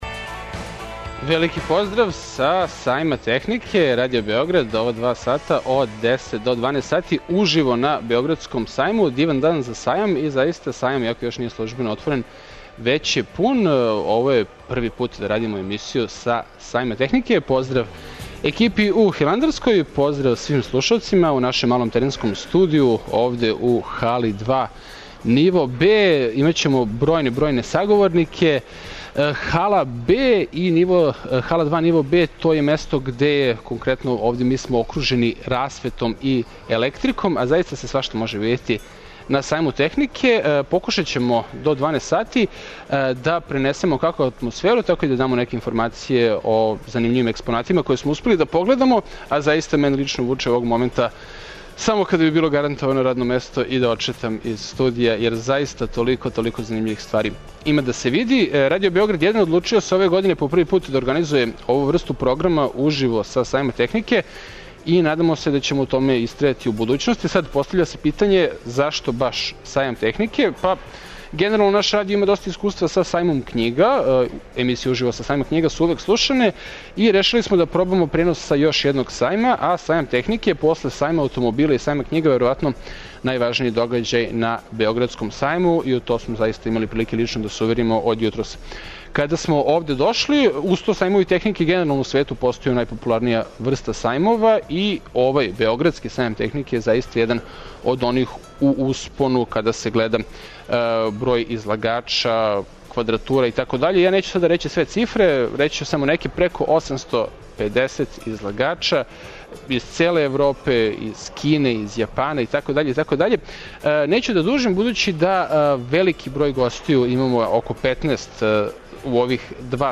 Радио Београд 1 емитује од 10 до 12 сати програм уживо са Међународног сајма технике и техничких достигнућа.
Гости у специјално припремљеном студију Радио Београда 1 биће иноватори из наше земље и представници института и удружења привредника, а очекујемо и понеког госта из иностранства, као и још неке високе званице.